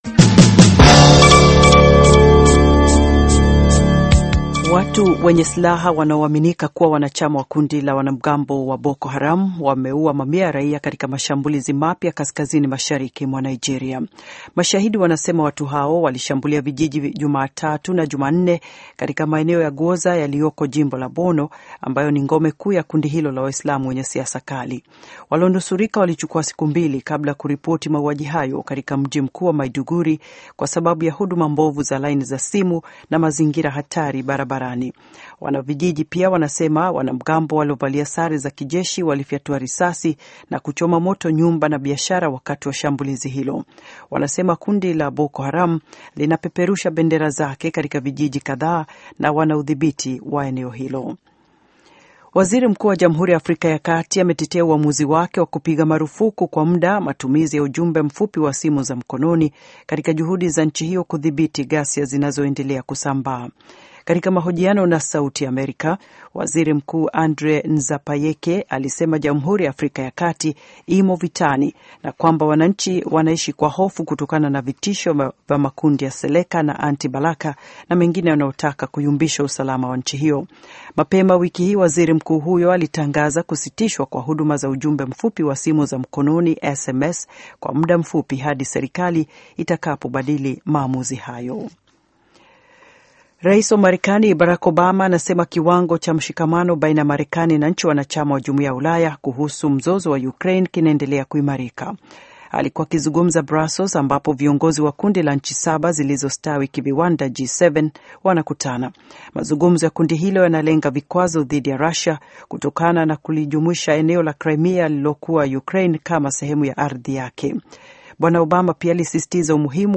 Taarifa ya Habari VOA Swahili - 5:42